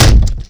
boat_heavy_3.wav